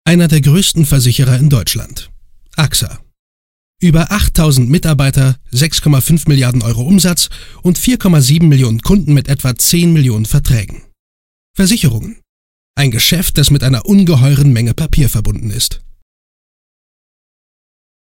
Junger, aber erfahrener Sprecher mit besonderer Stimme.
Sprechprobe: Industrie (Muttersprache):